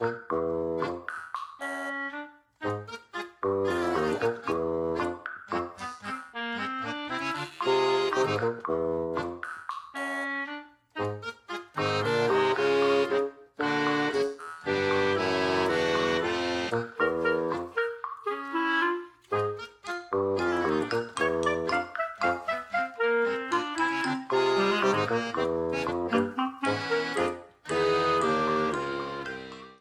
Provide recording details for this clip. Ripped from the game clipped to 30 seconds and applied fade-out